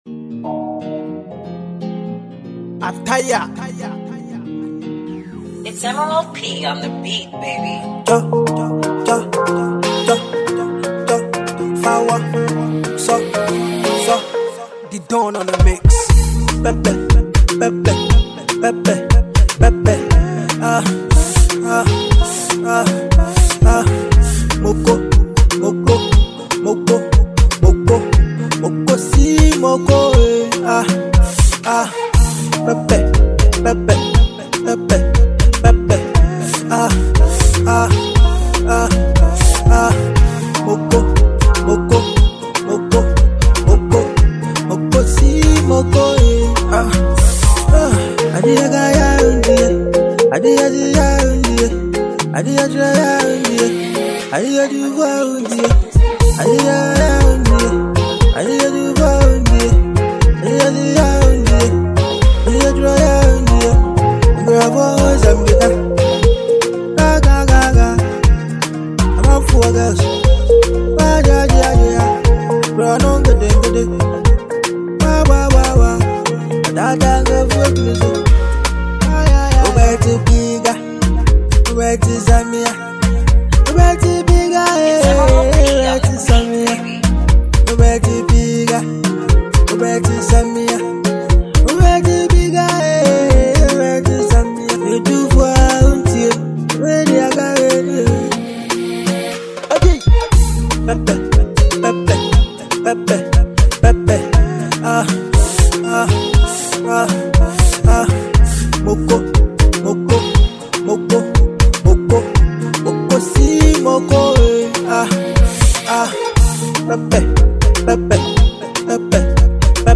xmas banger